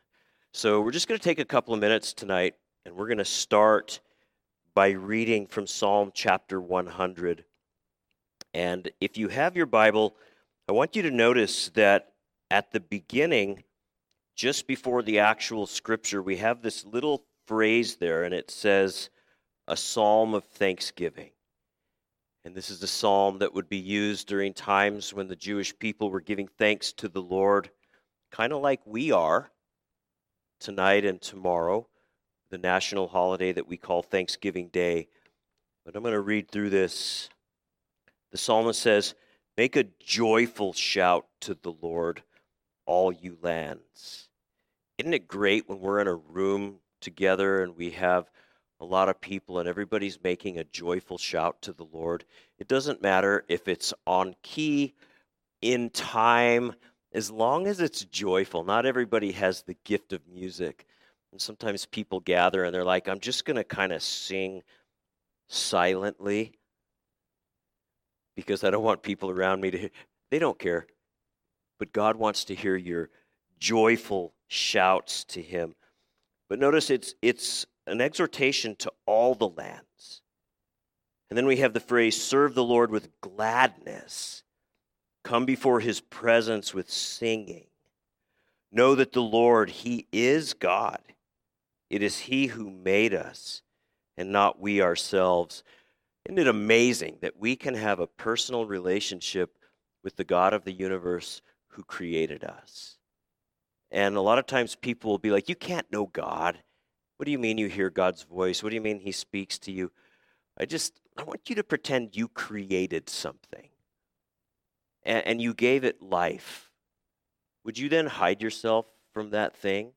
Thanksgiving Night of Worship